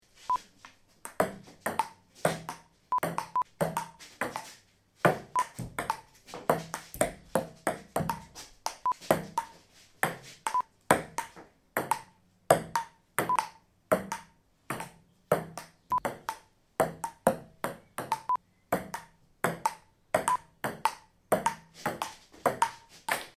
Playing Table Tennis (sound FX)
Playing table tennis, ping pong. Ball bounces on table, bats hit ball, foot movements of players. Ball hits net. Recorded in a garage. 16 bit 48kHz Stereo WAV.
PlayingTableTennis1_plip.mp3